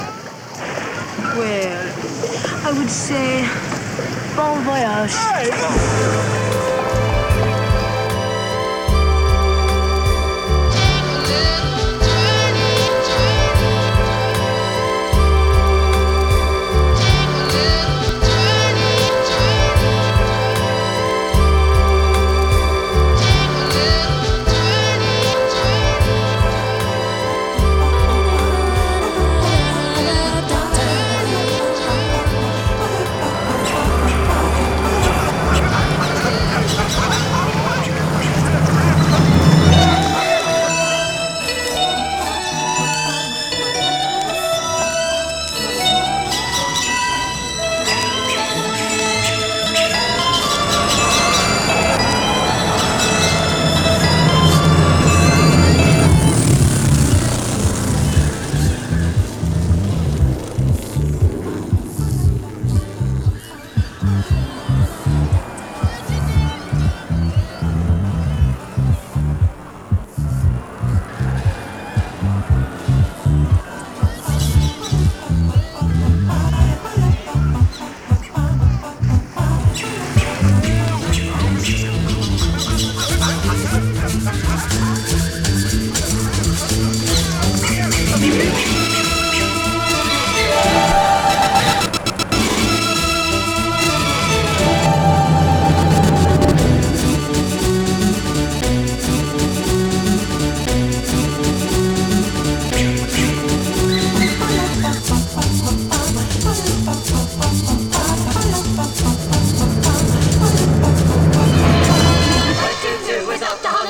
Жанр: electronic, hip hop, downtempo